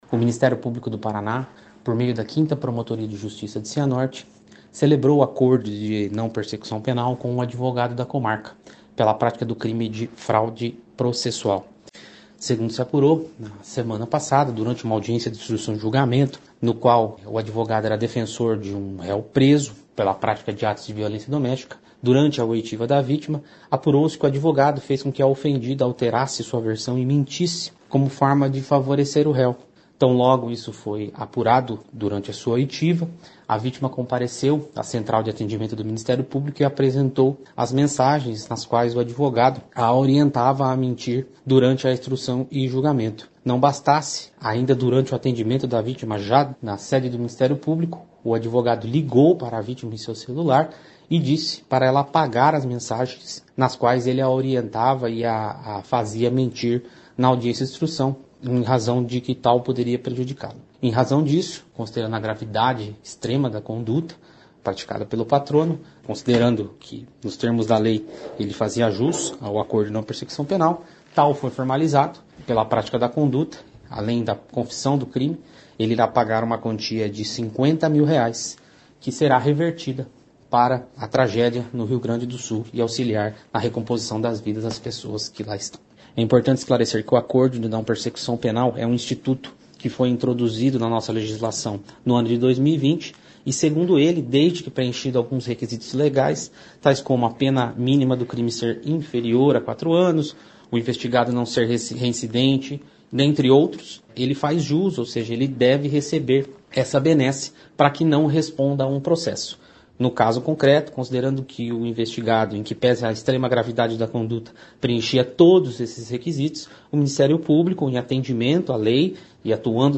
O promotor de Justiça José Paulo Montesino Gomes da Silva fala sobre o caso.